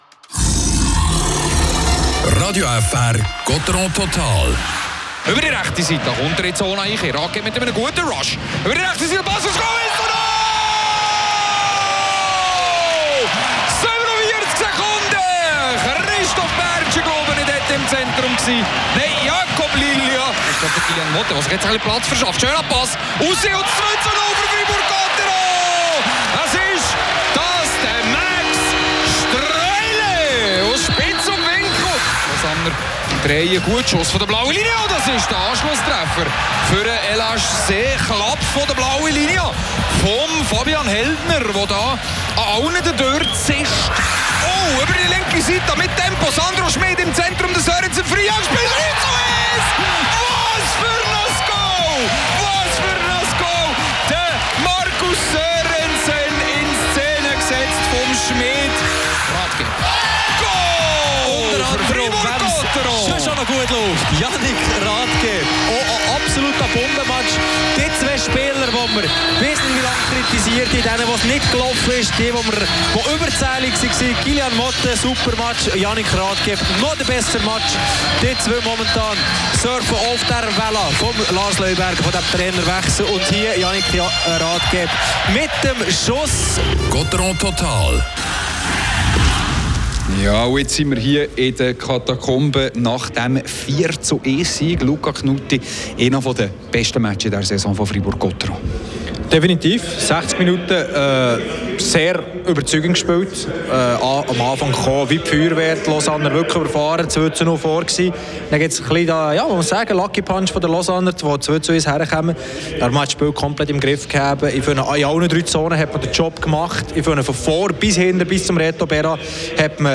Alle Emotionen unserer Kommentatoren während des Spiels